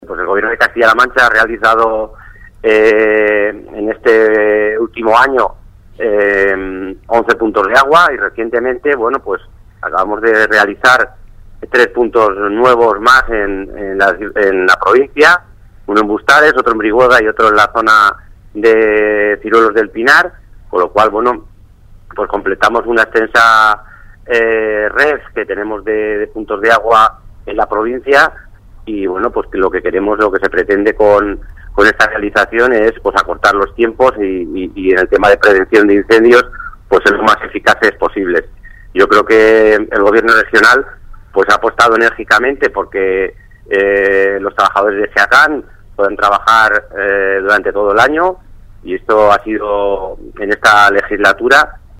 El director provincial de Agricultura, Medio Ambiente y Desarrollo Rural de Guadalajara habla sobre la ampliación de la red de puntos de agua contra incendios realizada en la provincia